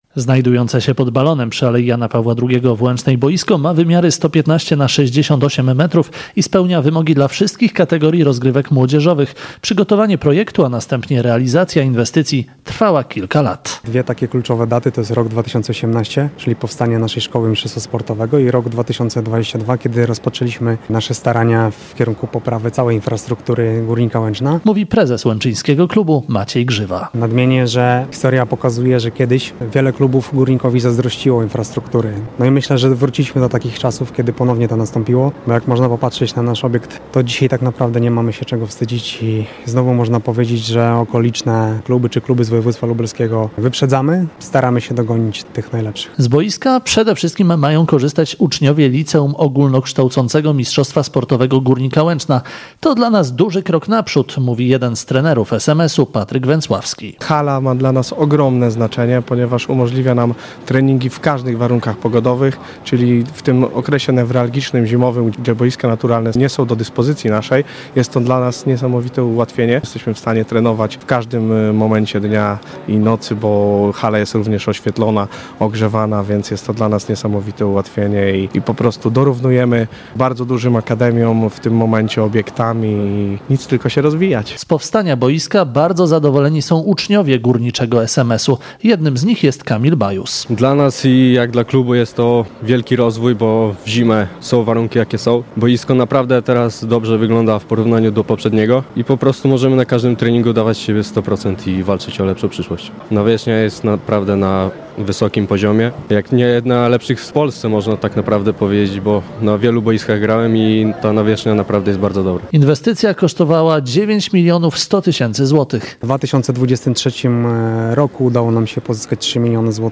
W uroczystości otwarcia brali udział samorządowcy, posłowie, przedstawiciele klubów sportowych i oczywiście uczniowie.